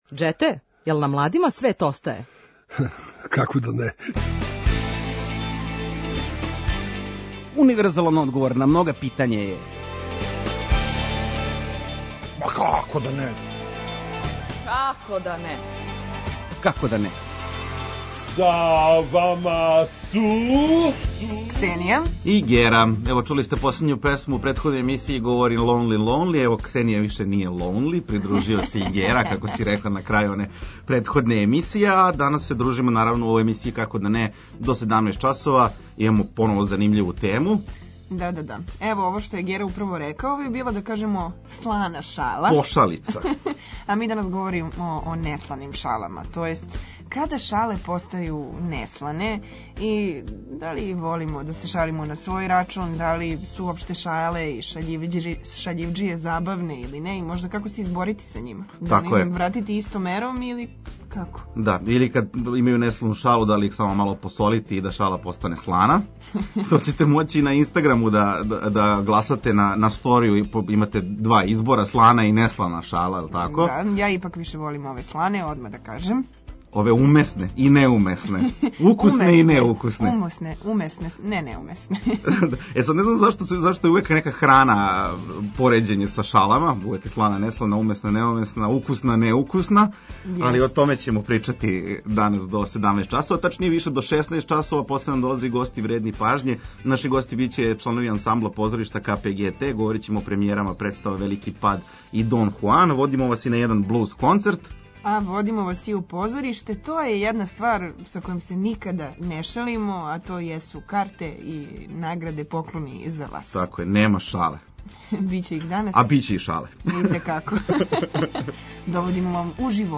Окосница емисије је „Дебата” у којој ћемо разменити мишљења о различитим темама и дилемама.
Наши „Гости вредни пажње” биће чланови ансамбла позоришта „КПГТ”. Говорићемо о премијерама представа „Велики пад” и „Дон Хуан”.